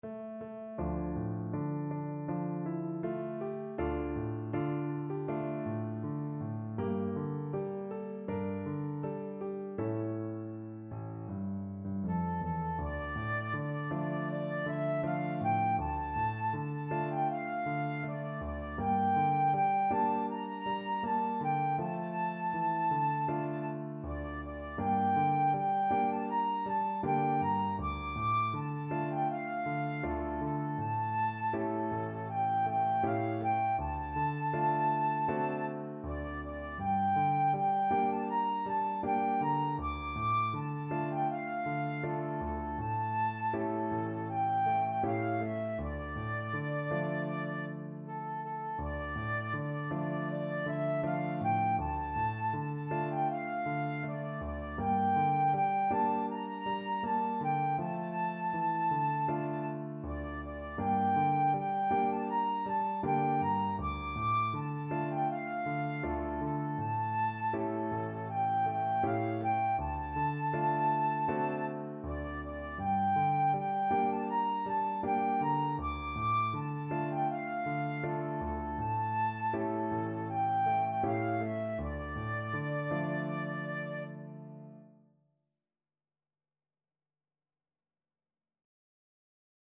Flute version
Flute
D minor (Sounding Pitch) (View more D minor Music for Flute )
Andante espressivo
4/4 (View more 4/4 Music)
Traditional (View more Traditional Flute Music)